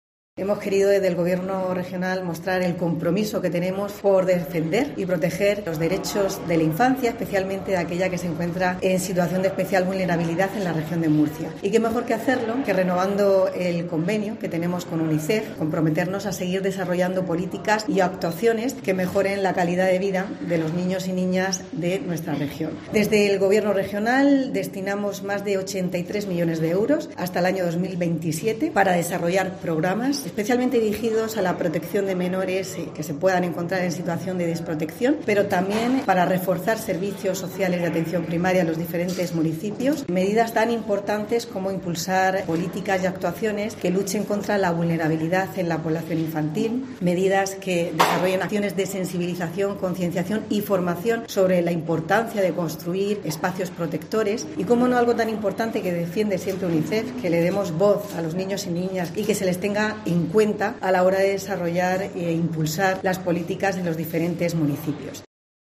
Conchita Ruiz, consejera de Política Social, Familias e Igualdad